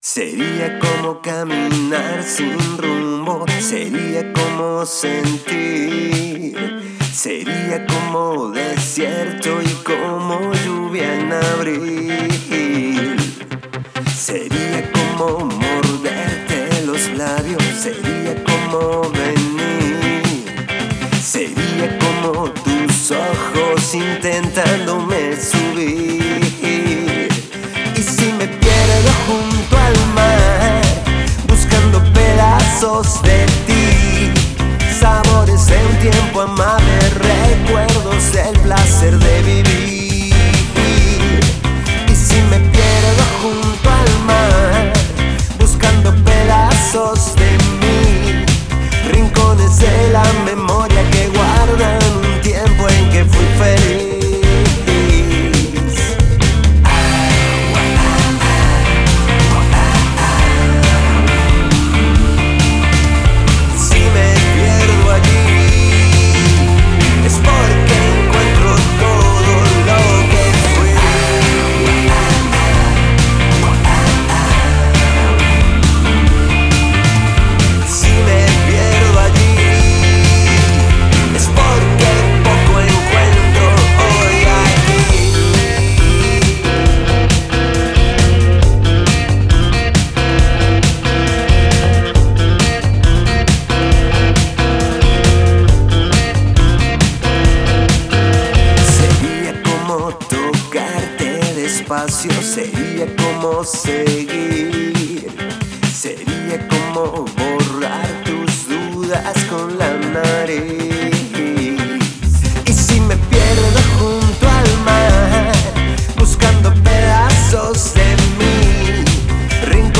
INGENIERO DE MEZCLA